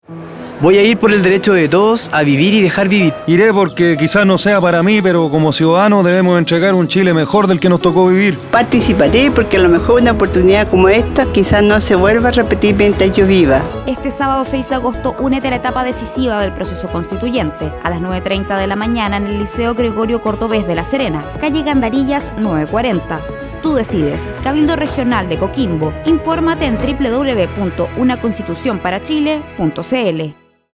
Audio promoción testimonial cabildos regionales, Región de Coquimbo 3